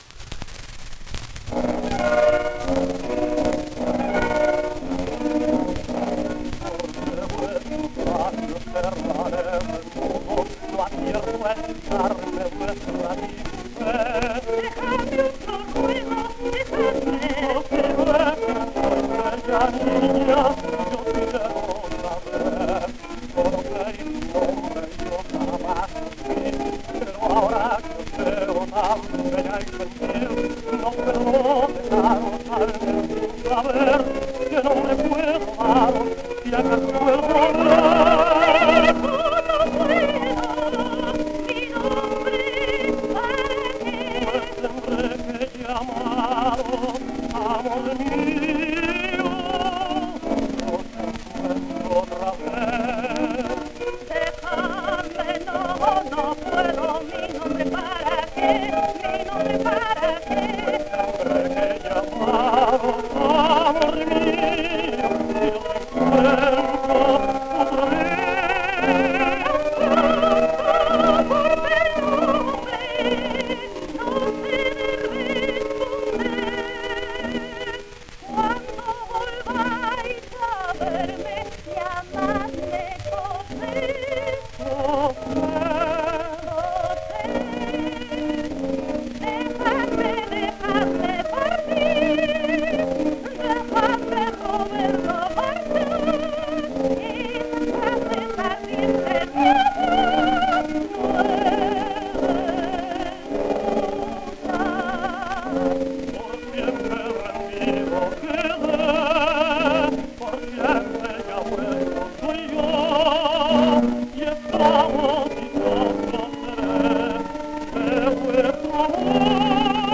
1 disco : 78 rpm ; 27 cm Intérpretes